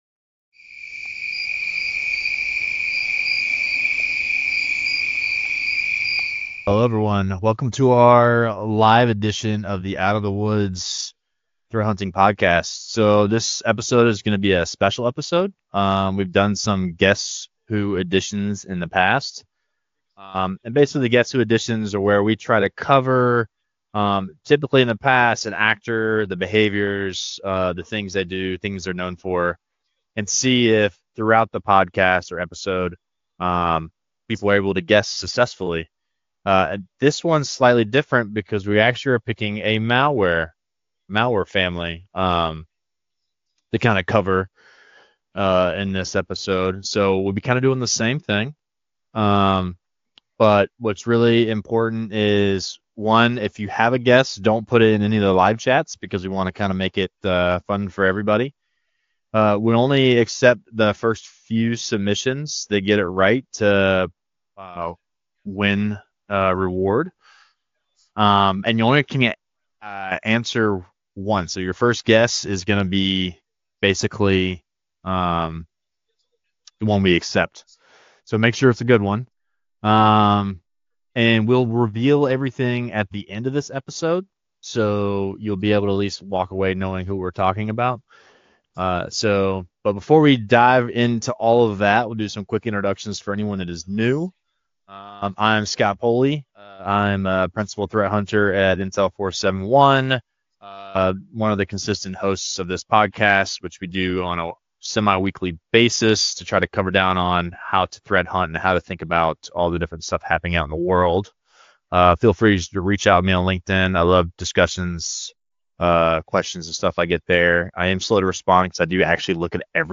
[LIVE] Guess Who: The Malware Edition
Can You Identify the Malware Family?Out of the Woods: The Threat Hunting Podcast returns with another live, interactive edition designed to test how you analyze malicious activity. This session will focus on a specific malware family, revealing its behavior in stages as our hosts walk through execution patterns, infrastructure clues, and operational tradecraft.